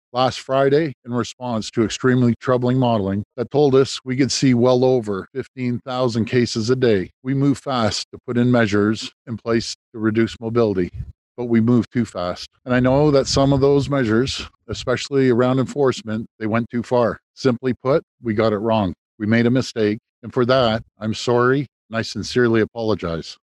Thursday Ontario Premier Doug Ford held a news conference from isolation where he’s been for a few days due to a staffer testing positive for COVID. He addressed two main issues, the paid sick leave program and income but also touched on recent provincial decisions made by his government which was the reason for an opening apology.